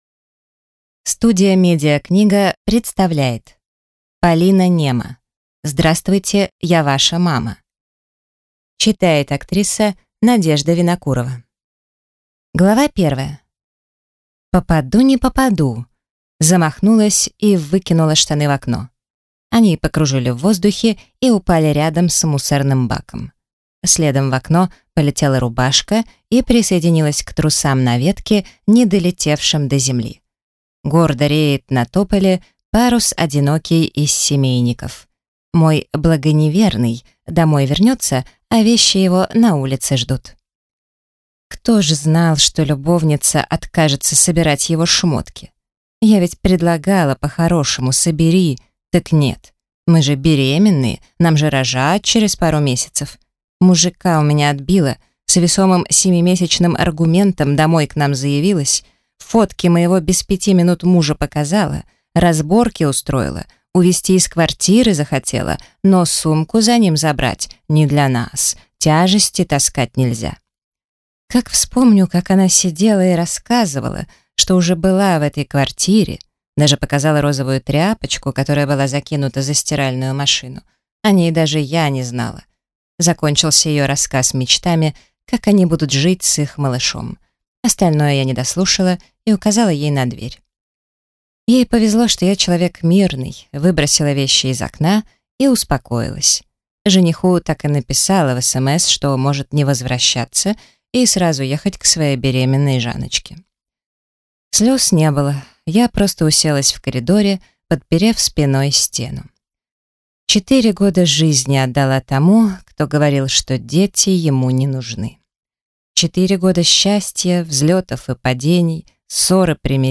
Aудиокнига Здравствуйте, я ваша мама!